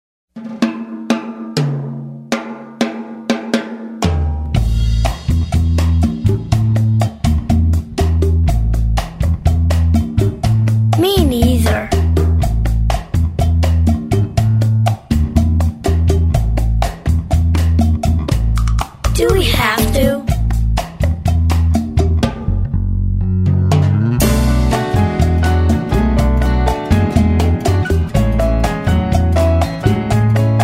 Vocal and Instrumental MP3 Tracks with Printable Lyrics
▪ The full instrumental track